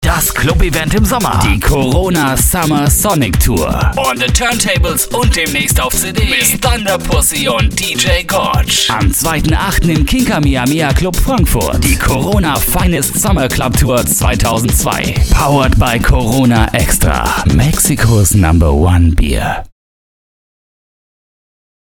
Jung-dynamische Werbestimme, Radiomoderator, 39 Jahre
Sprechprobe: Werbung (Muttersprache):
german young voice over artist